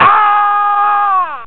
schrei